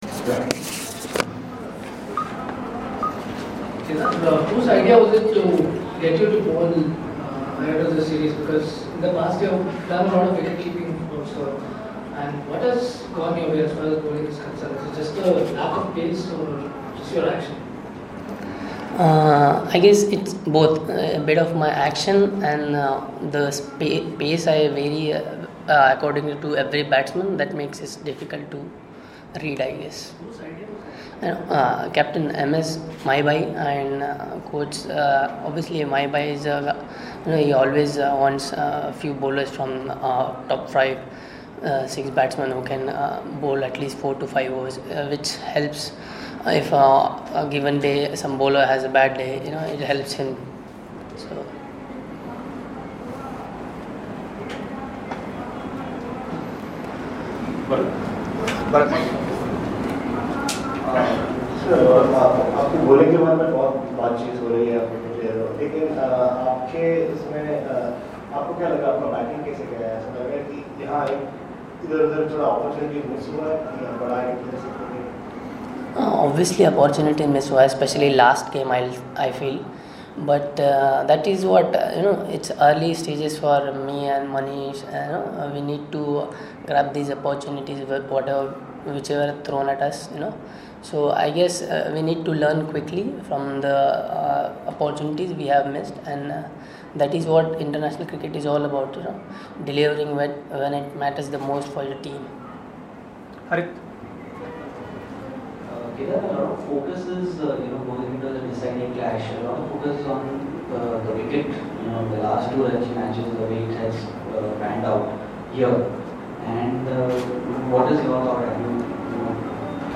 LISTEN: Kedar Jadhav speaking on the eve of 5th ODI in Vizag